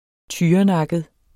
Udtale [ ˈtyːʌˌnɑgəð ]